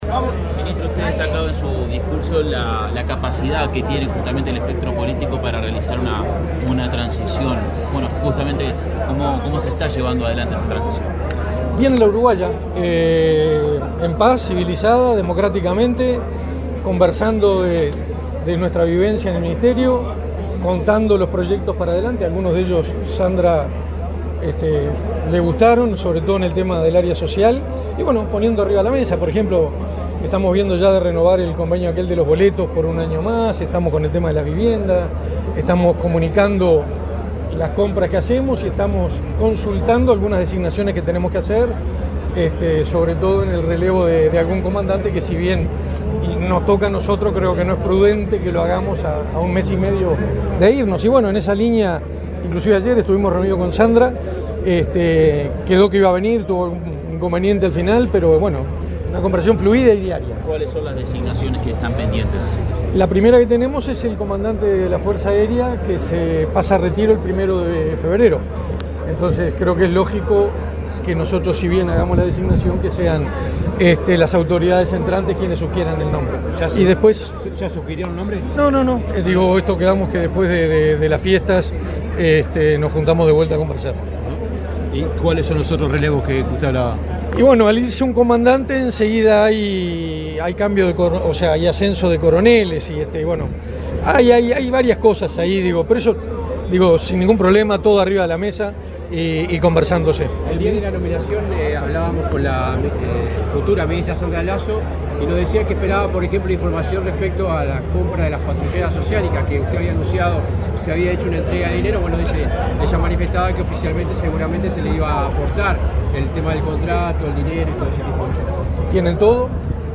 Declaraciones del ministro de Defensa Nacional, Armando Castaingdebat
Declaraciones del ministro de Defensa Nacional, Armando Castaingdebat 20/12/2024 Compartir Facebook X Copiar enlace WhatsApp LinkedIn Este viernes 20, el ministro de Defensa Nacional, Armando Castaingdebat, encabezó la ceremonia del 196.º aniversario de la creación del Ministerio de Defensa Nacional. Luego, dialogó con la prensa.